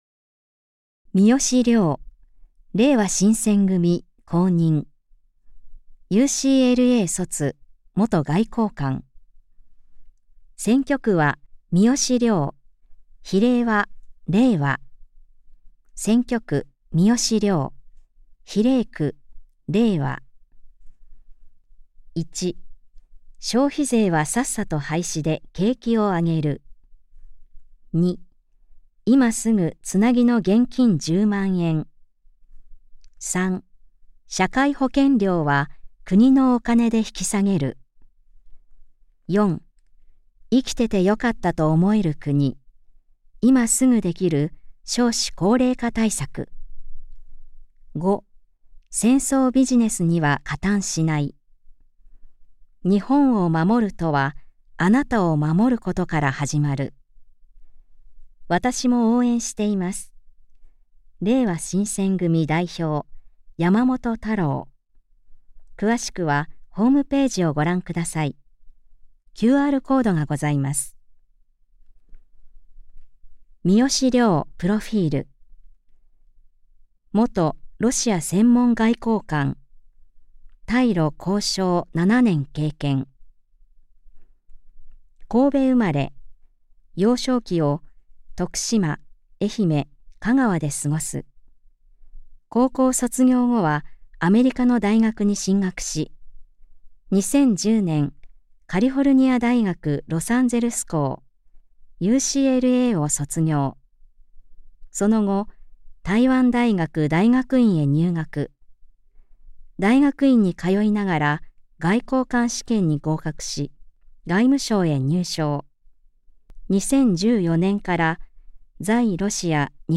衆議院議員総選挙　候補者・名簿届出政党等情報（選挙公報）（音声読み上げ用）